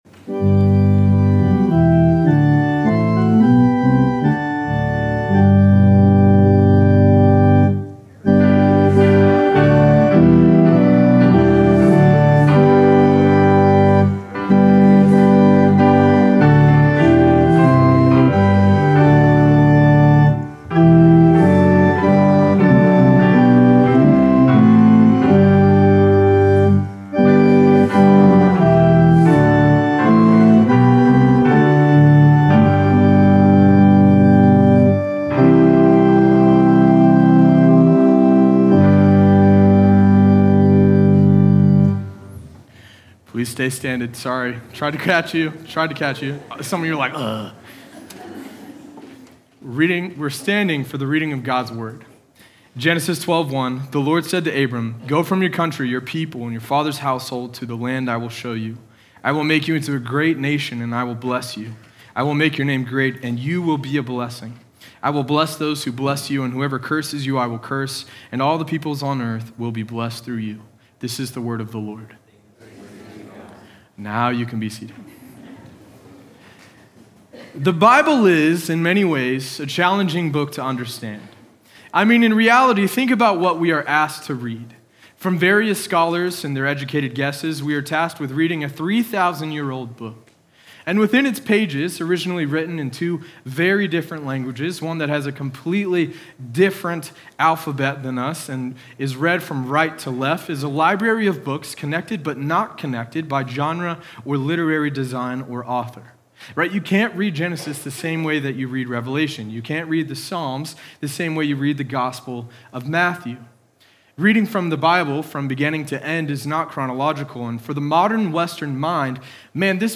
sermon audio 0928.mp3